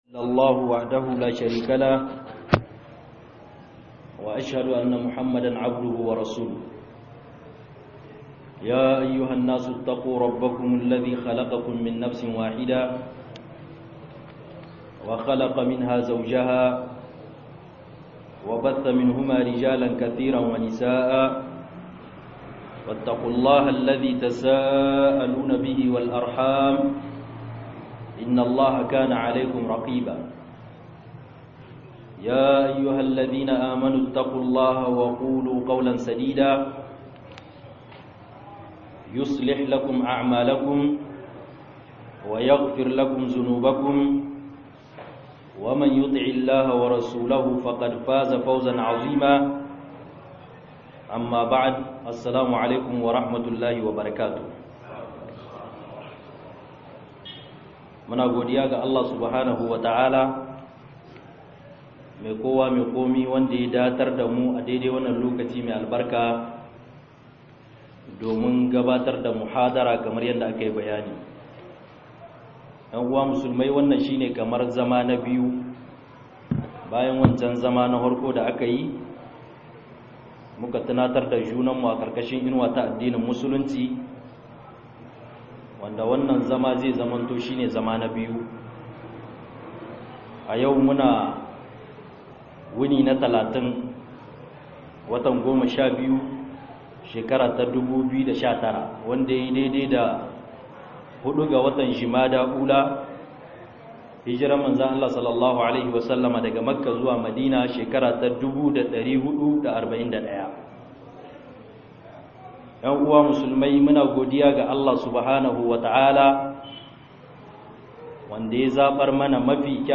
INA-MAFITA - MUHADARA